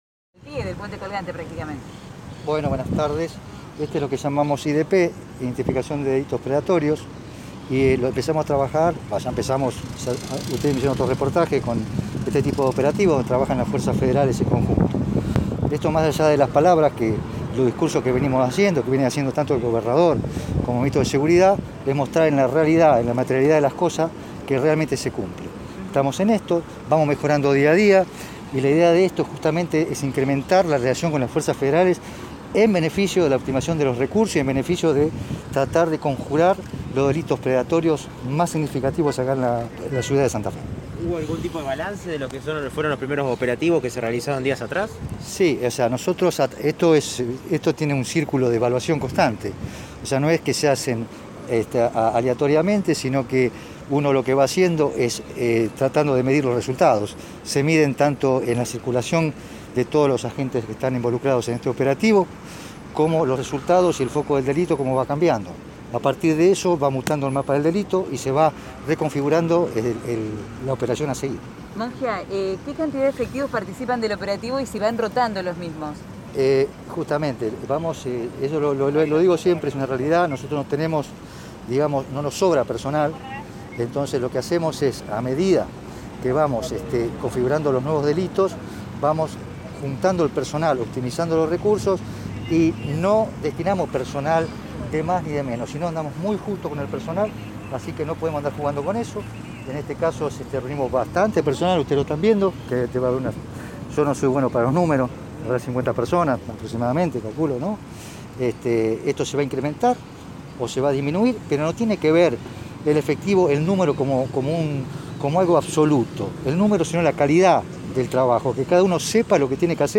En diálogo con Radio EME, Alberto Mongia sostuvo que «la idea de este tipo de operativos es incrementar la relación con las fuerzas federales en beneficio de tratar de conjurar los delitos predatorios mas significativos en la ciudad de Santa Fe«.